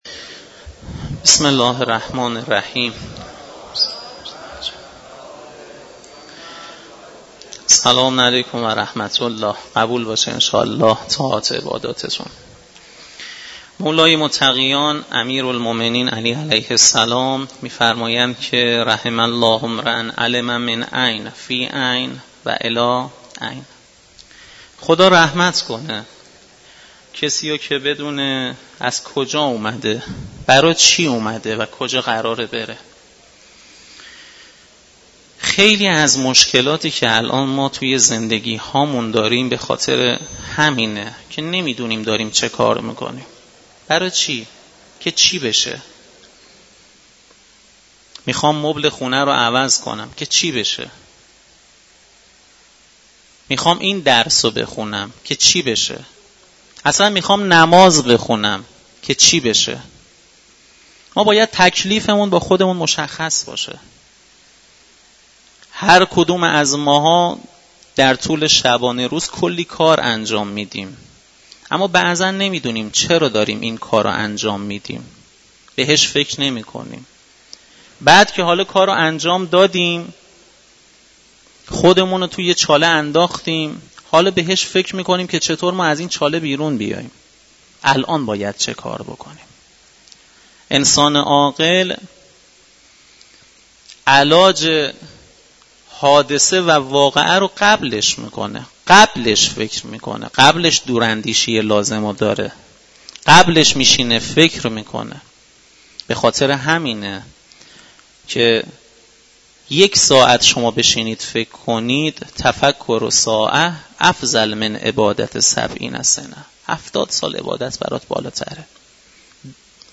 سخنرانی
در مسجد دانشگاه کاشان